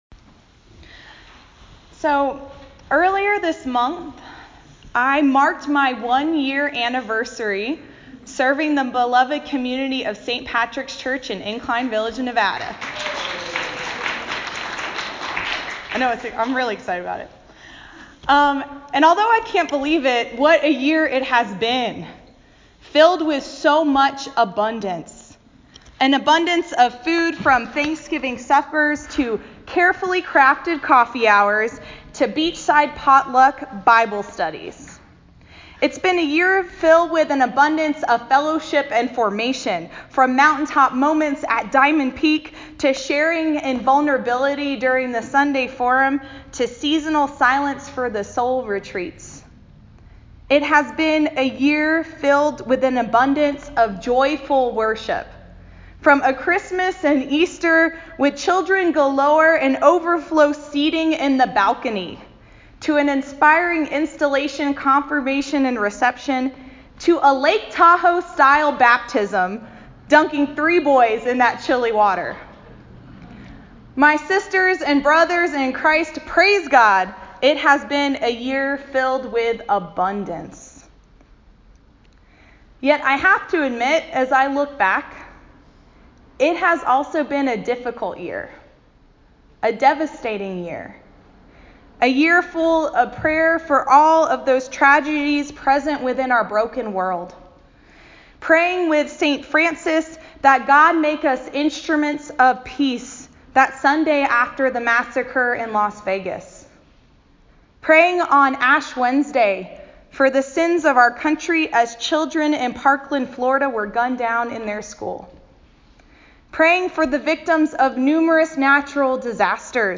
A Sermon for Proper 21, Year B